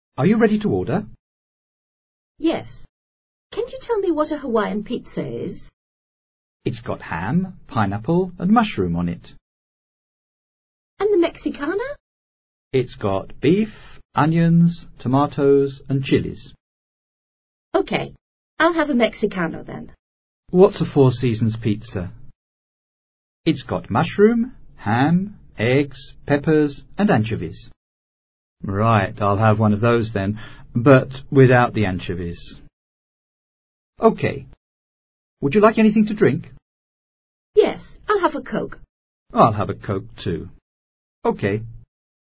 Diálogo que recrea una conversación entre un mesero y dos clientes de un restaurant, quienes están decidiendo qué pizza ordenar.